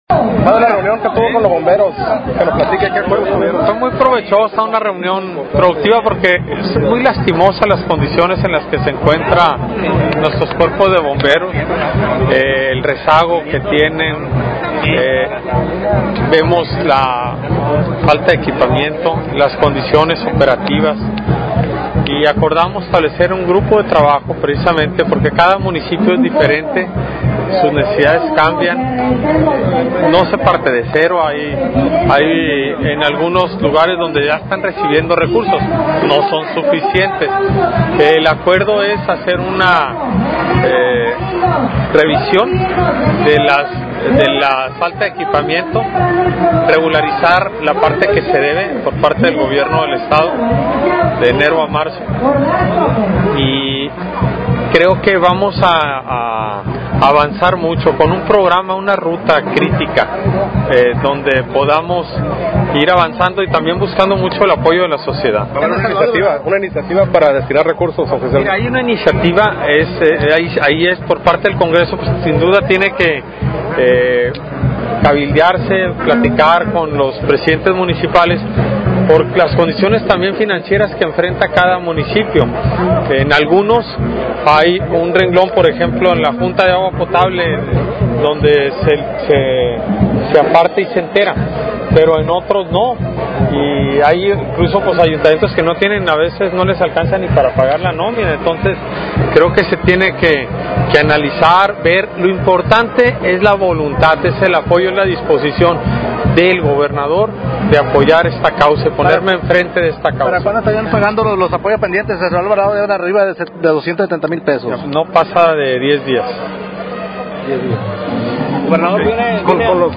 ENTREVISTA CONCEDIDA POR EL GOBERNADOR DEL ESTADO QUIRINO ORDAZ, DURANTE SU GIRA POR EL MUNICIPIO DE MOCORITO